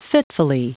Prononciation du mot fitfully en anglais (fichier audio)
Prononciation du mot : fitfully